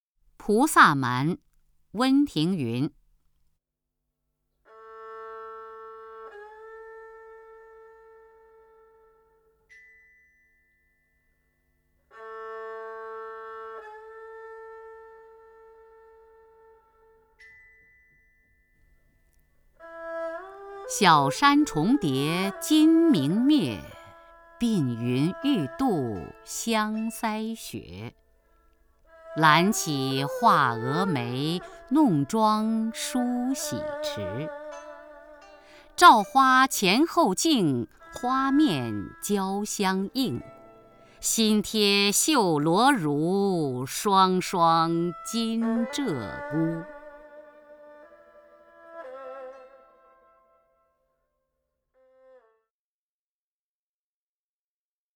首页 视听 名家朗诵欣赏 雅坤
雅坤朗诵：《菩萨蛮·小山重叠金明灭》(（唐）温庭筠)　/ （唐）温庭筠